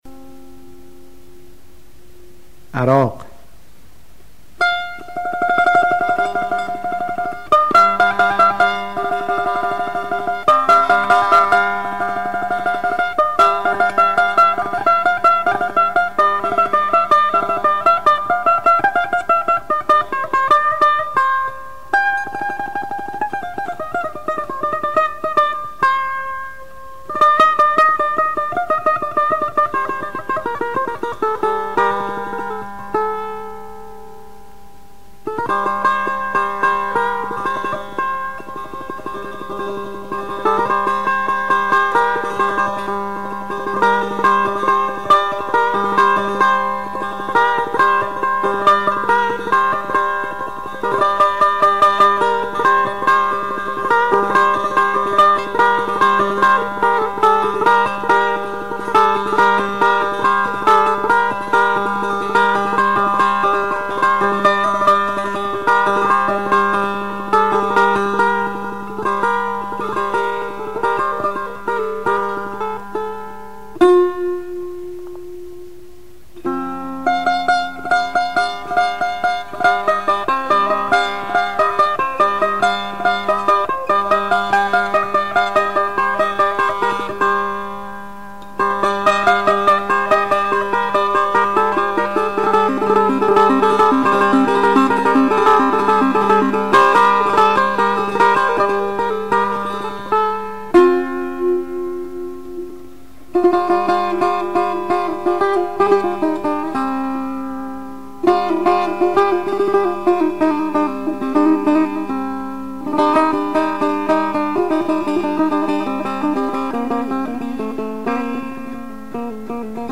آواز افشاری ردیف میرزا عبدالله سه تار
استاد طلایی در اجرای خود با ساز سه تار، به خوبی توانسته است همانندی‌های این آواز را با دستگاه‌های نوا و ماهور به نمایش گذارد.